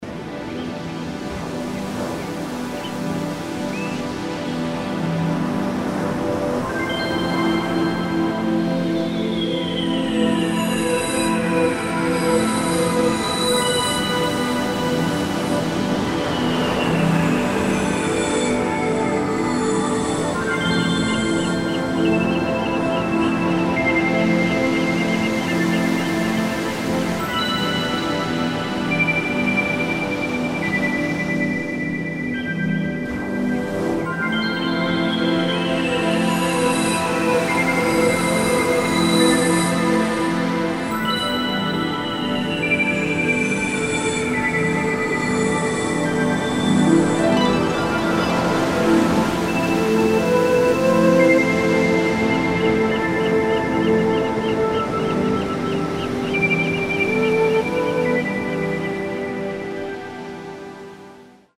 Аудиокнига Колыбельные природы | Библиотека аудиокниг